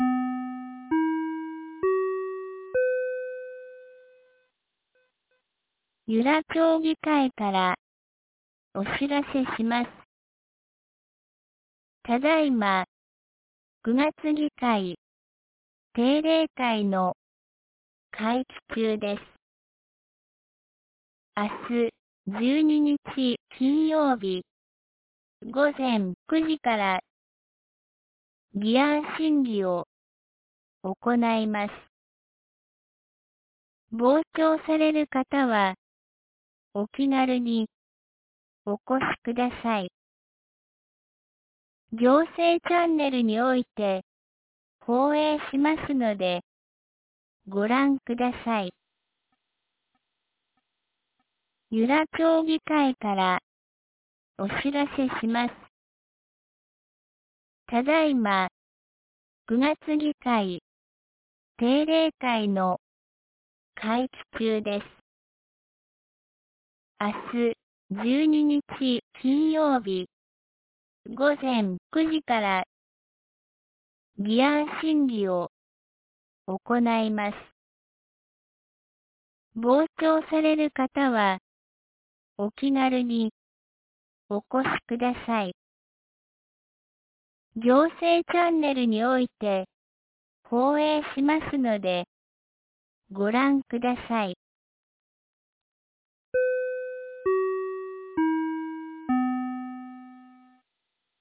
2025年09月11日 17時12分に、由良町から全地区へ放送がありました。